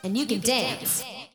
andyoucandance.wav